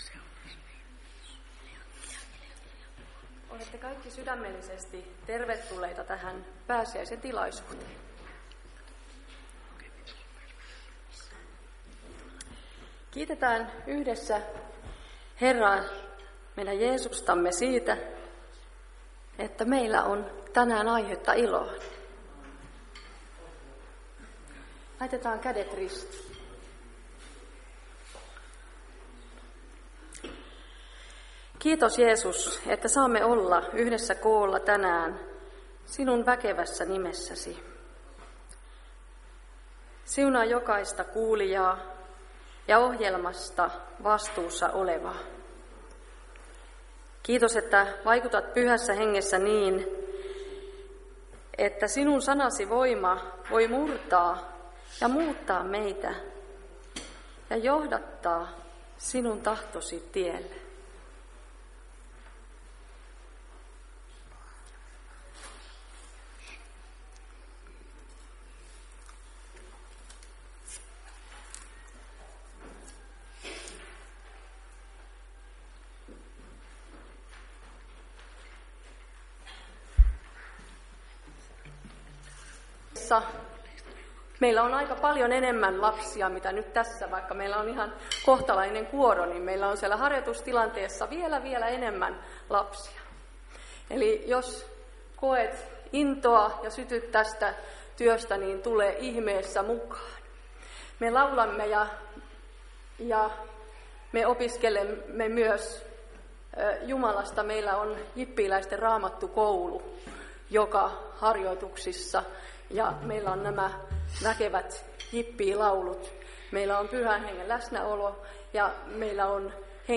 Päiväkokous 9.4.2023